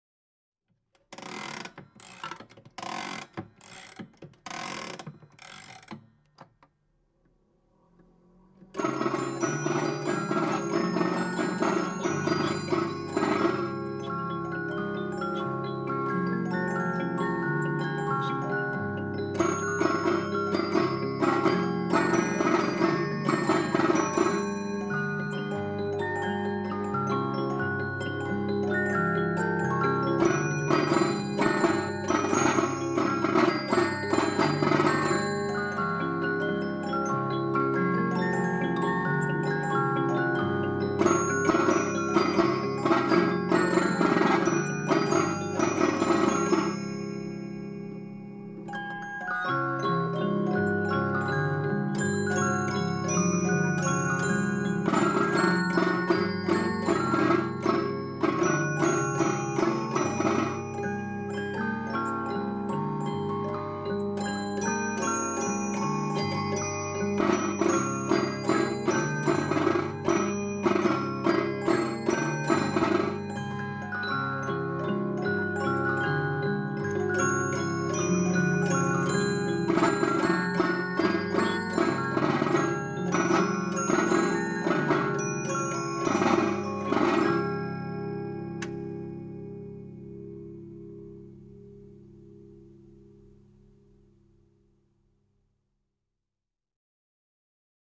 Boîte à musique – Music Box (made by Nicole Frères, Genève 1889): I. Winding up – II. Strolling home with Rosey – III. He is an Englishman